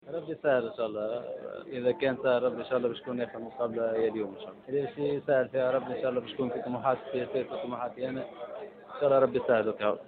اكد لاعب النادي الصفاقسي علي معلول في تصريح خاص لجوهرة اف ام انه لا يستبعد مغادرة فريق عاصمة الجنوب بعد العروض المغرية التي وصلته خاصة من بعض الاندية الفرنسية .
علي معلول : لاعب النادي الصفاقسي